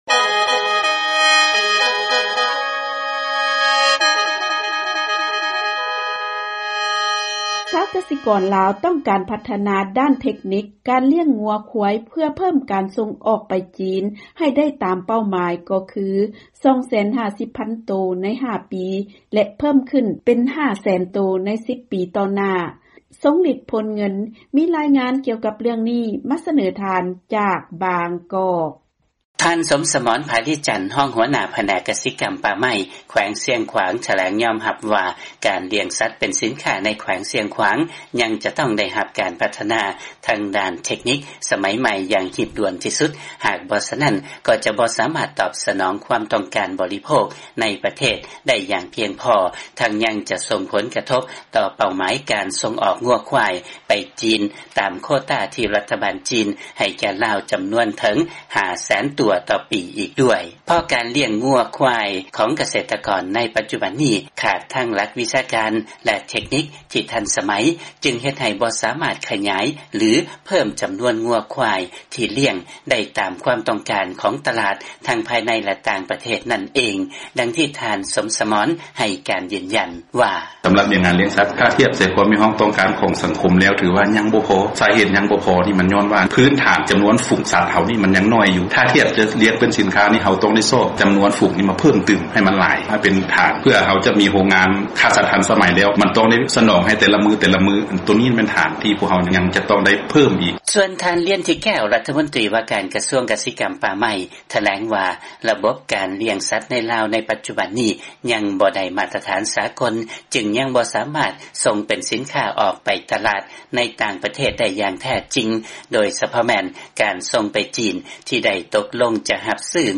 ເຊີນຟັງລາຍງານກ່ຽວກັບການລ້ຽງງົວ-ຄວາຍໃນລາວເພື່ອສົ່ງອອກໄປຈີນ